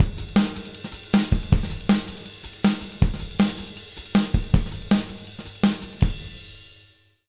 160JUNGLE3-L.wav